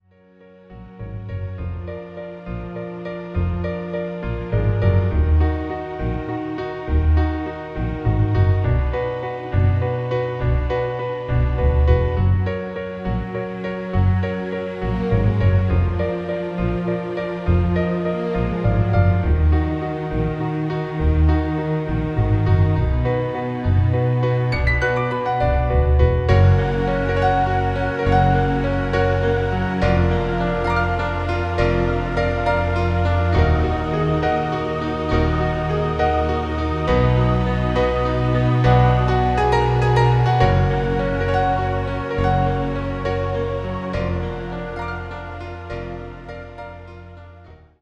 sans voix finale